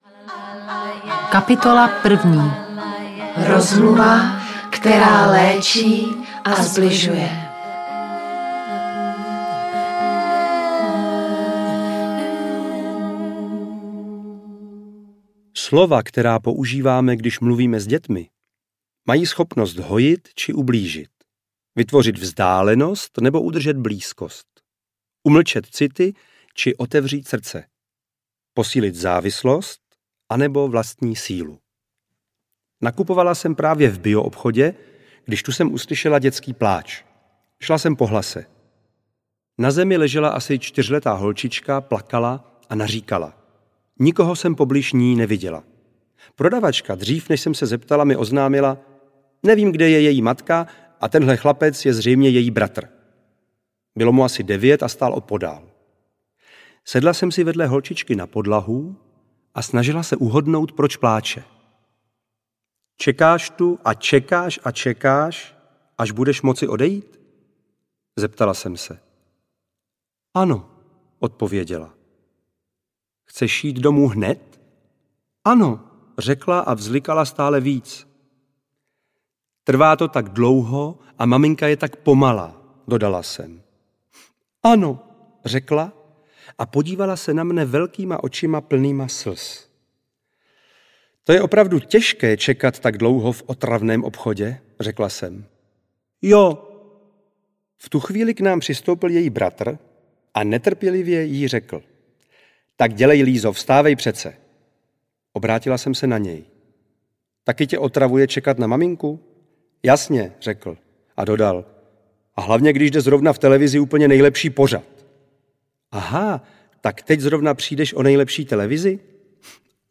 Vychováváme děti a rosteme s nimi audiokniha
Ukázka z knihy
• InterpretJaroslav Dušek